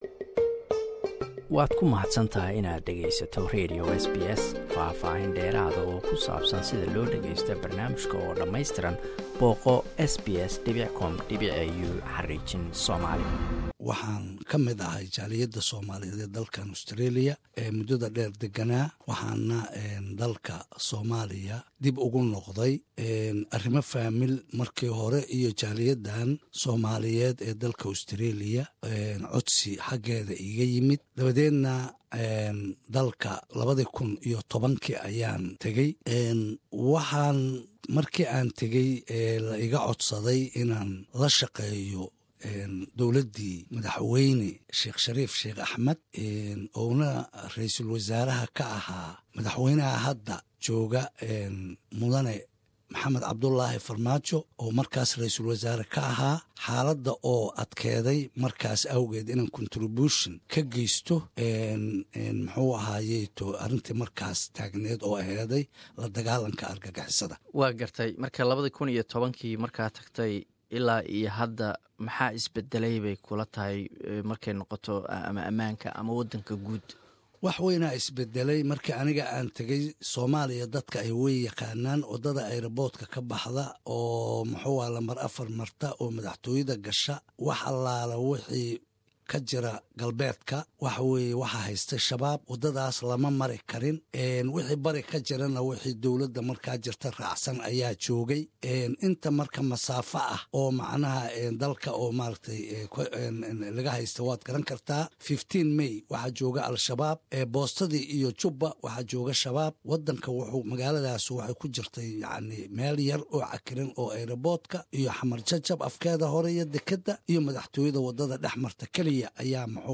Waraysi: Janaraal Qaafoow